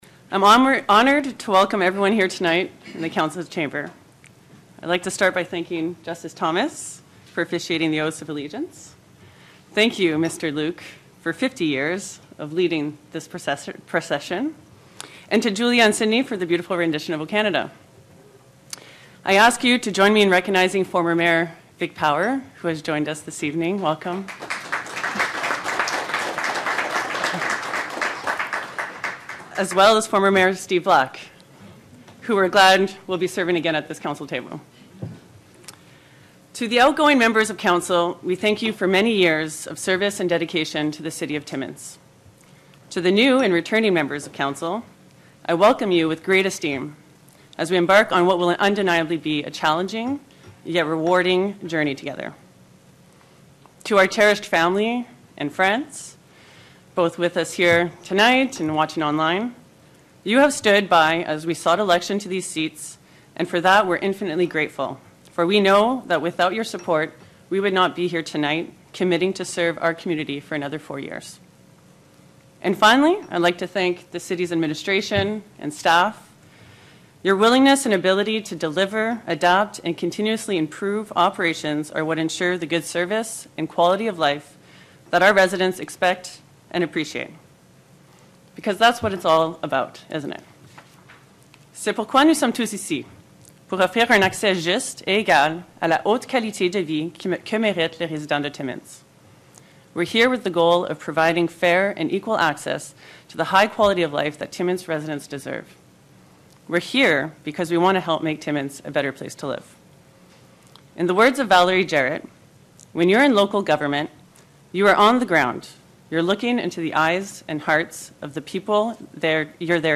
A new era has begun at Timmins City Hall, with the inauguration of the council elected to lead the city the next four years.
After she and her councillors each took an oath of office, Mayor Michelle Boileau addressed the gathering. She got choked up when she noted that history had been made, with the mayoralty going from Kristin Murray to another woman.
Boileau-inaugural-speech-for-web.mp3